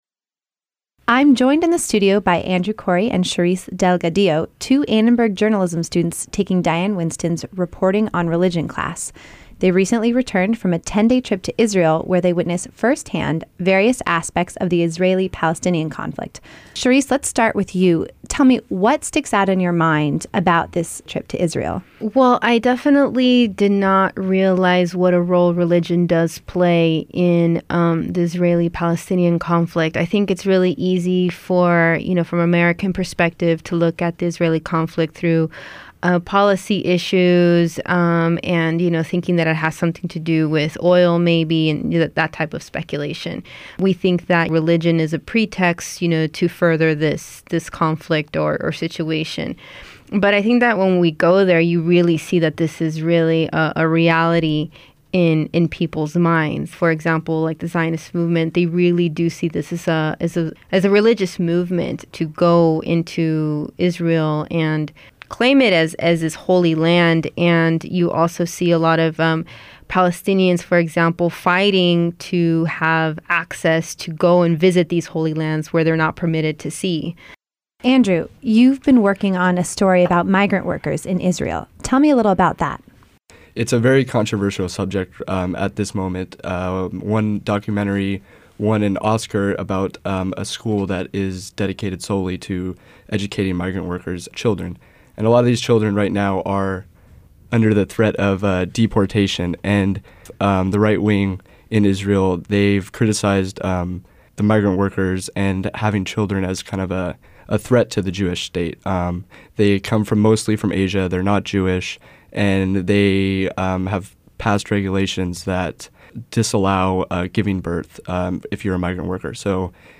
Israel_host_Interview.mp3